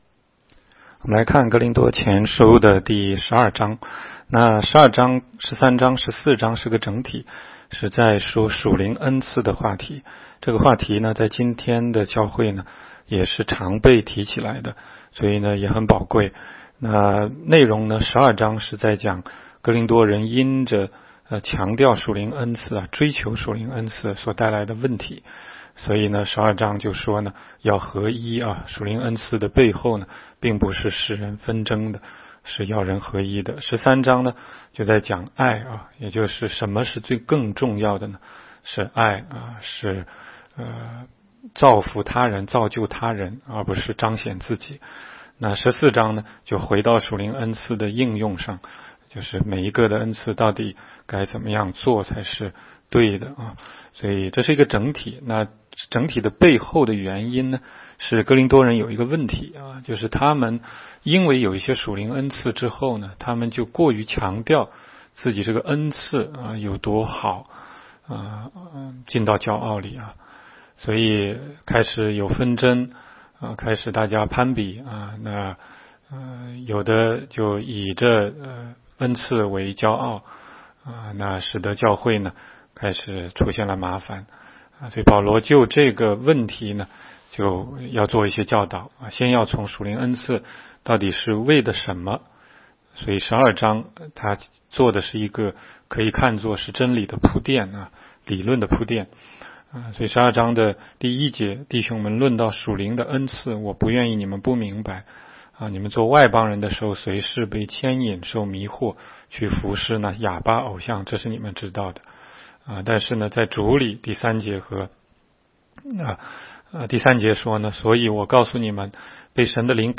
16街讲道录音 - 每日读经